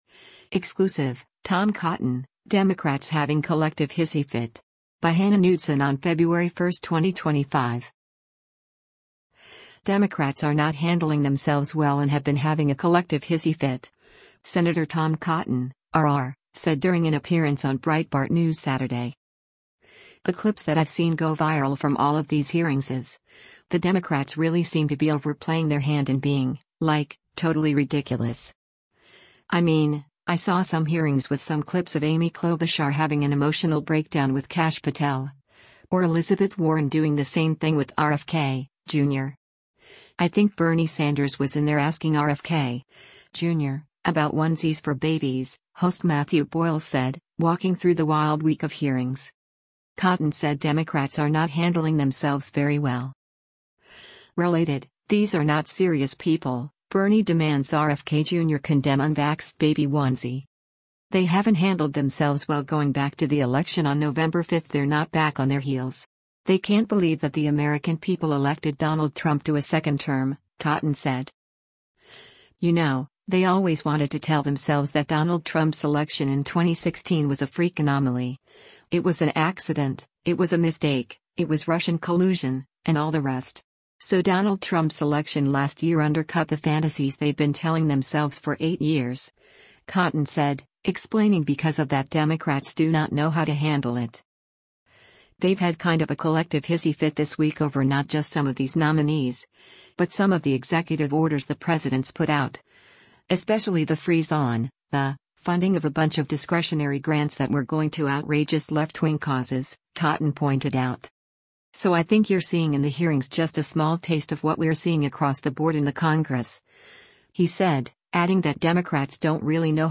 Democrats are not handling themselves well and have been having a “collective hissy fit,” Sen. Tom Cotton (R-AR) said during an appearance on Breitbart News Saturday.